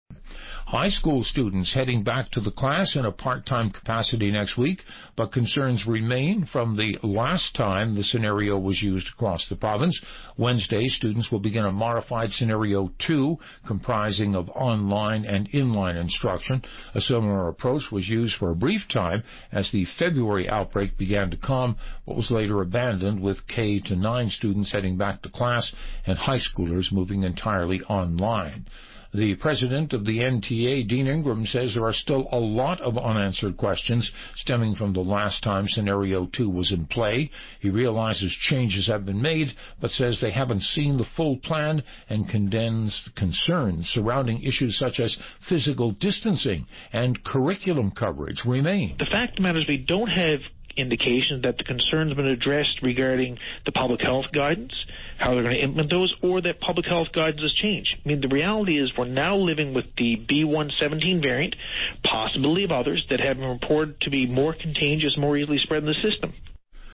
Media Interview - VOCM 1pm News Apr 10, 2021